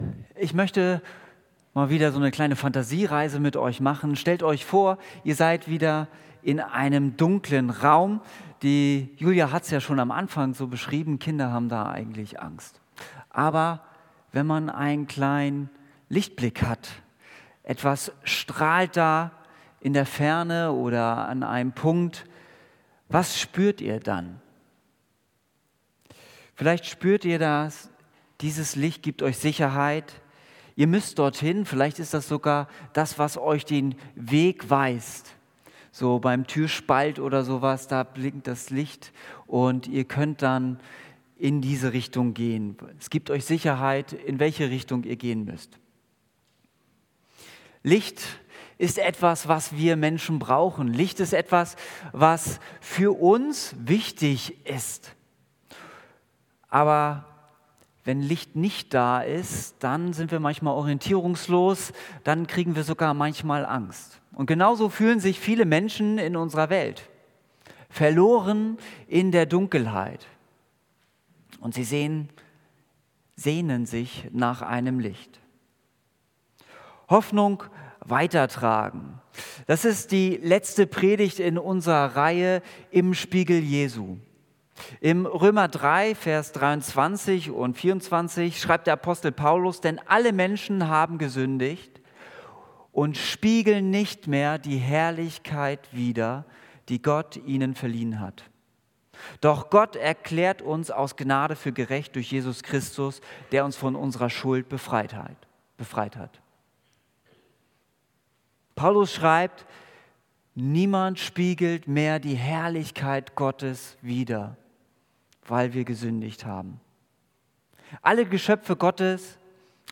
Predigt Im Spiegel Jesu